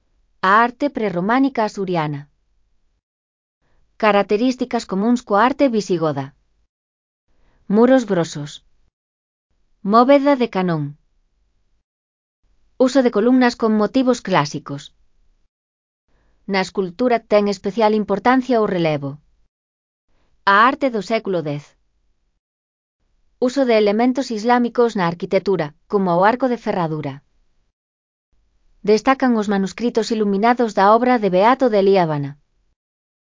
Lectura facilitada